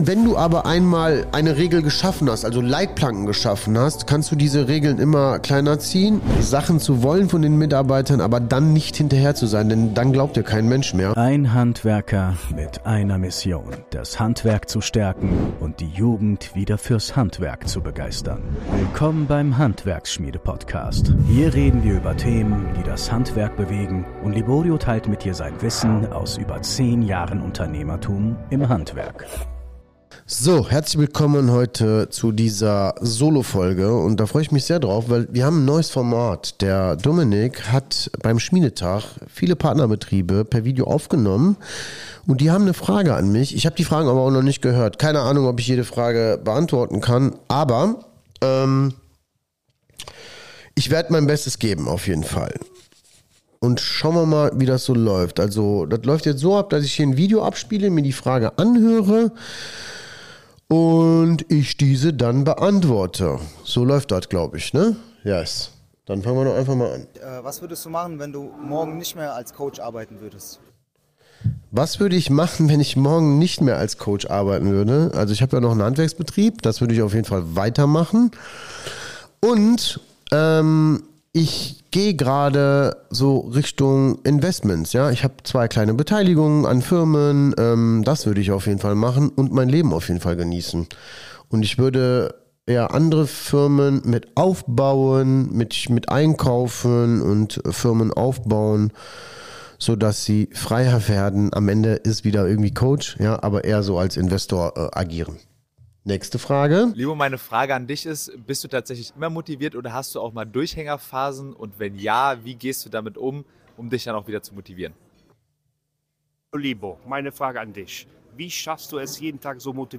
Ich stelle mich euren Fragen | Solofolge